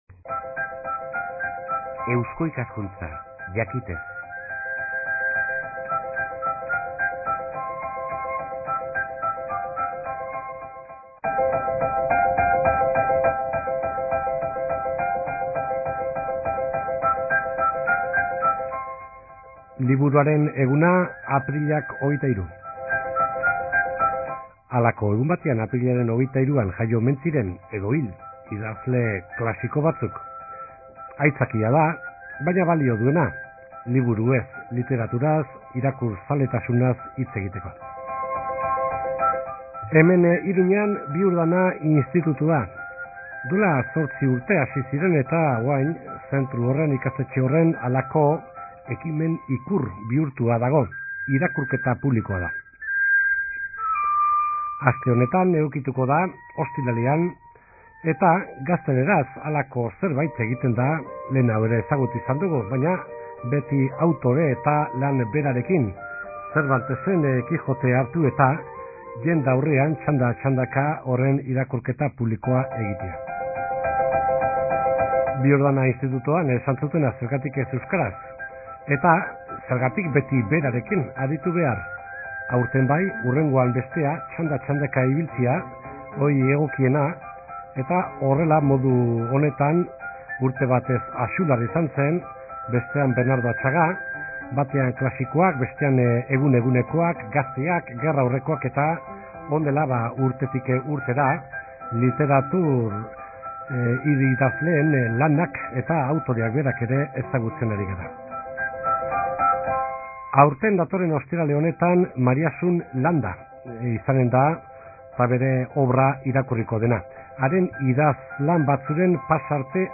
Mariasun Landaren idazkien irakurraldia